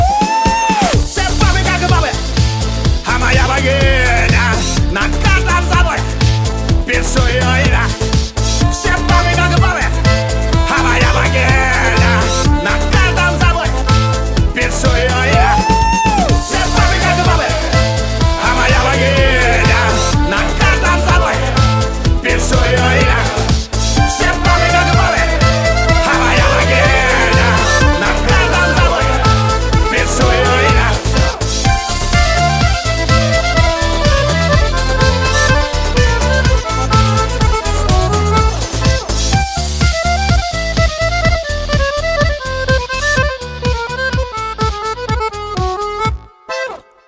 • Качество: 132, Stereo
веселые
шуточная песня